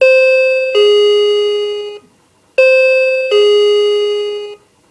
叮咚门铃声
描述：经典的叮咚门铃声、提示音
Tag: 混音 叮咚 门铃 提示音